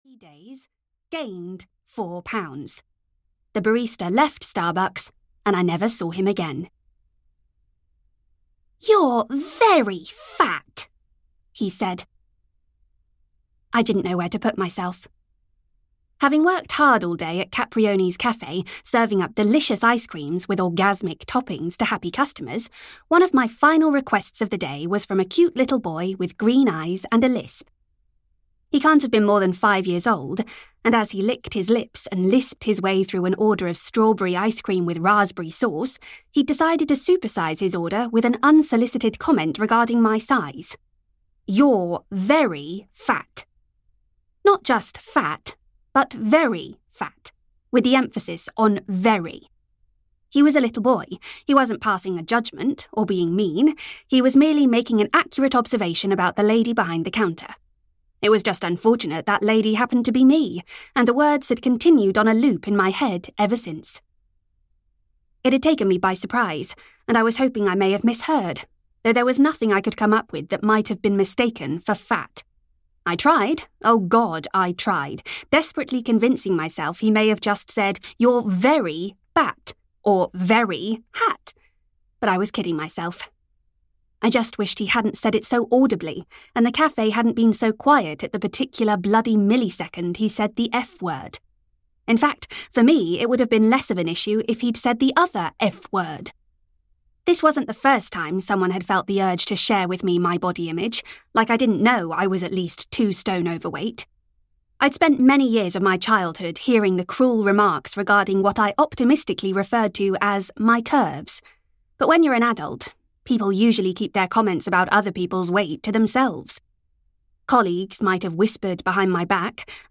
Audio knihaCurves, Kisses and Chocolate Ice-Cream (EN)
Ukázka z knihy